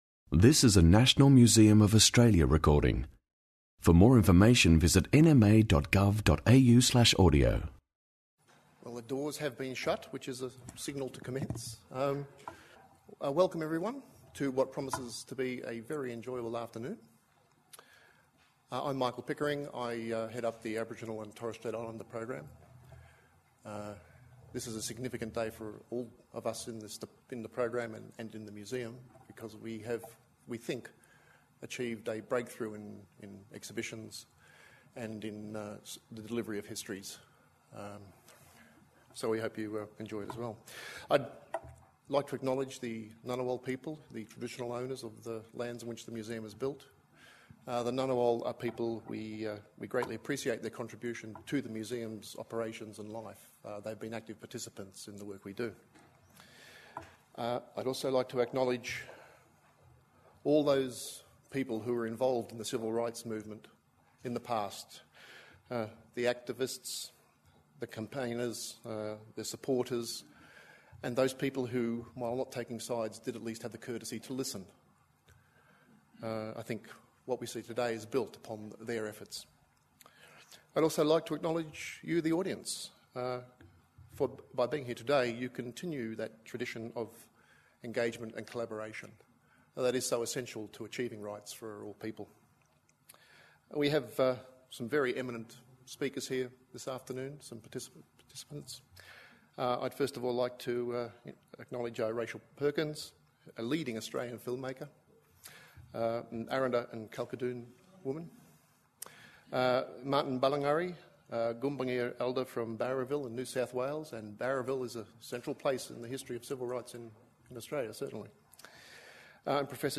Get Up, Stand Up public forum